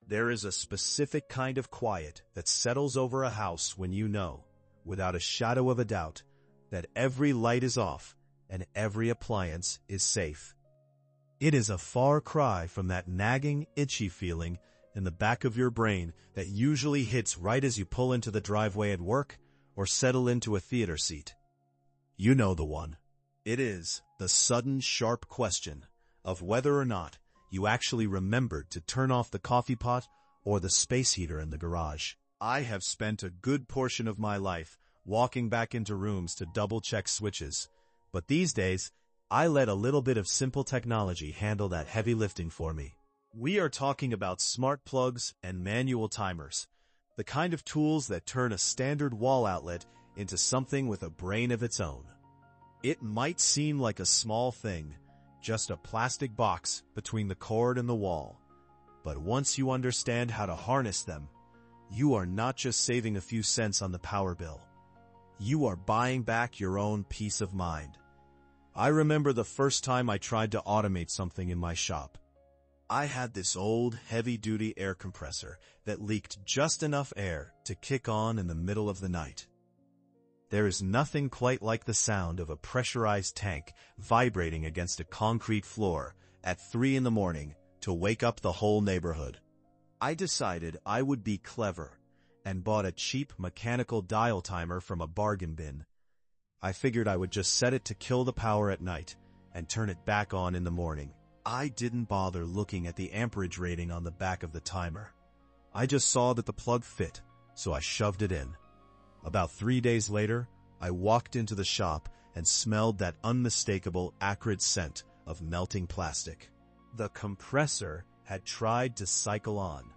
We sit down as mentor and apprentice to discuss the tactile differences between the gear-driven hum of a manual dial and the digital relay of a smart device, focusing on how to read amperage ratings so you never have to worry about a melted circuit.